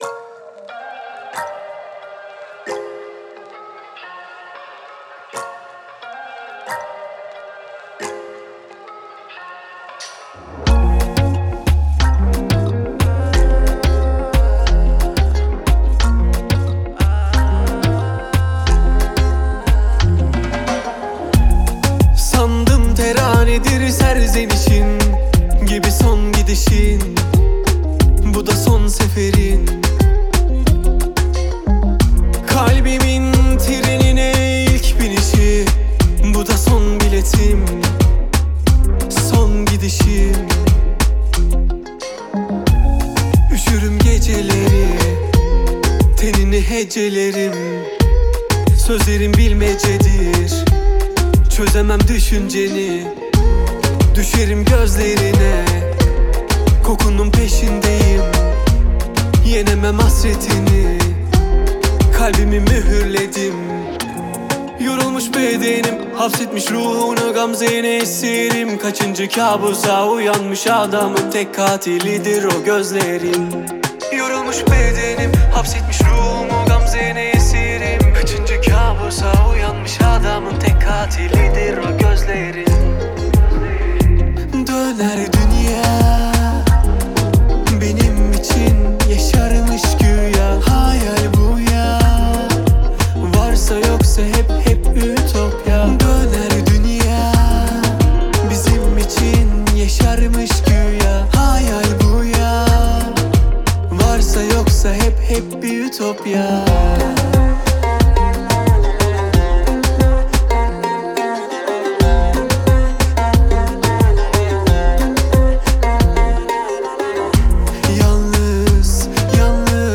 выполненная в жанре поп с элементами электронной музыки.